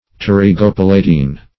Search Result for " pterygopalatine" : The Collaborative International Dictionary of English v.0.48: Pterygopalatine \Pter`y*go*pal"a*tine\, a. [Pterygoid + palatine.]
pterygopalatine.mp3